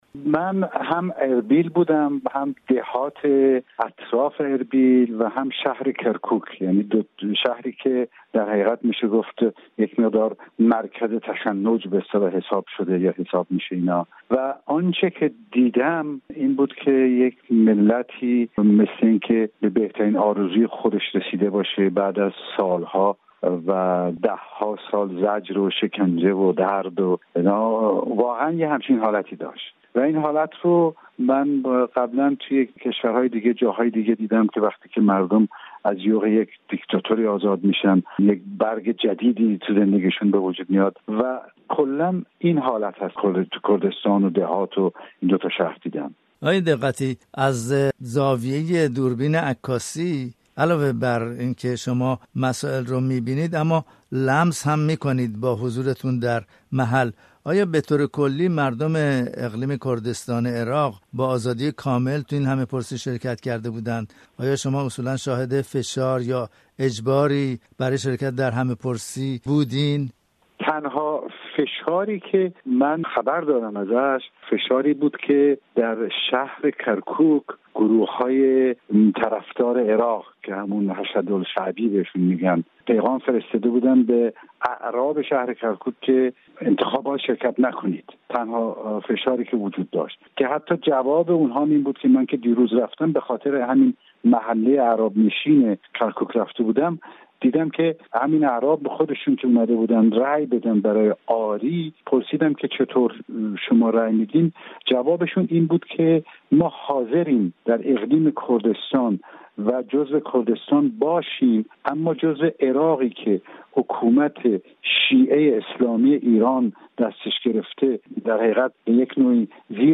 رادیو فردا در گفت وگو با رضا دقتی در اربیل، ابتدا در باره وضعیت این منطقه در شمال عراق و حال و هوای مردم اقلیم کردستان را از او پرسیده است.